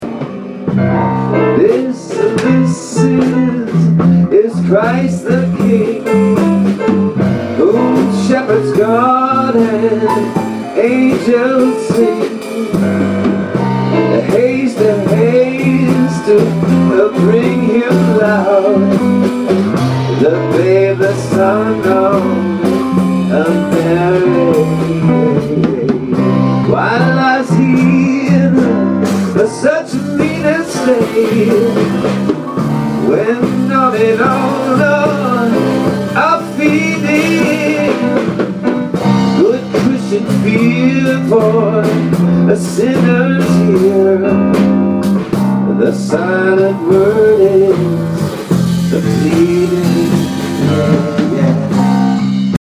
What Child is This.  A swanky rendition of the classic spiritual.  Recorded on my iPhone in a strangerÕs basement.  Not fine production value, but great attitude.